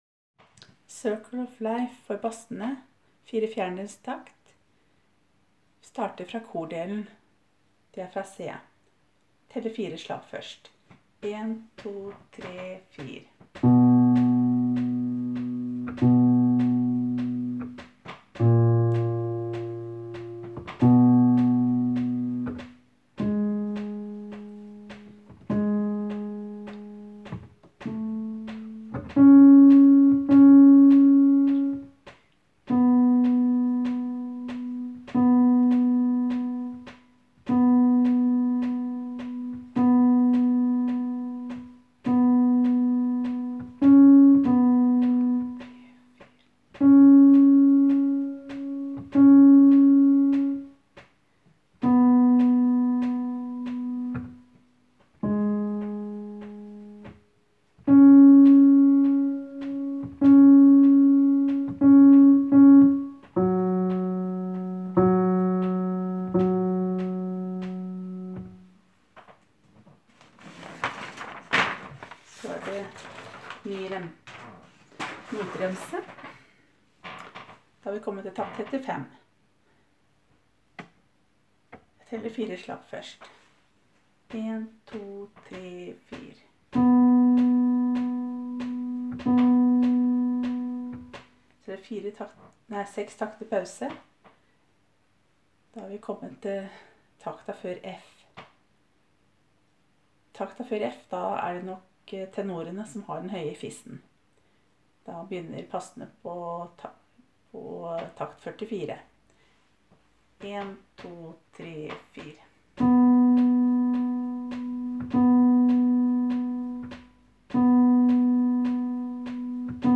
Jul 2017 Bass (begge konserter)
Cirkle-of-life-Basser.m4a